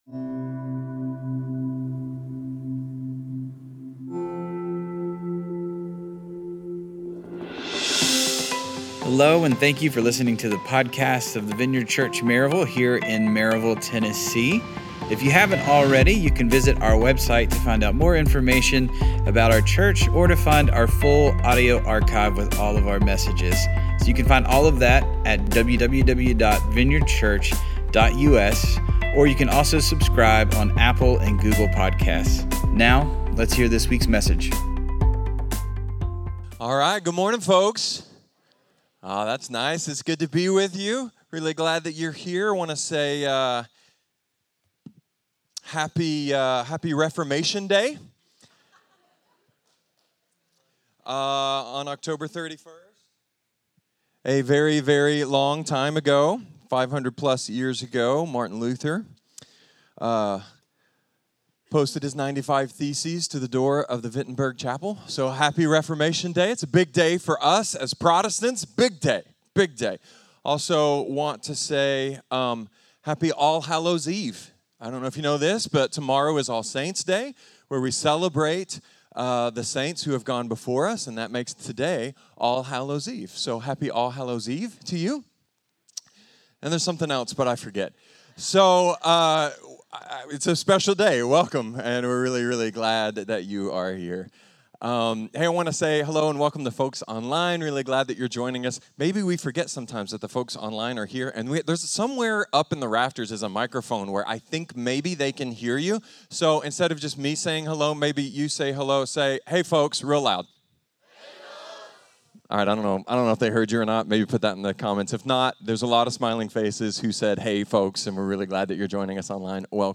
A sermon about cultural shifts, Jesus’ clearest teachings, newly-minted-minorities, and how a certain kind of devotion makes us pretty much invincible.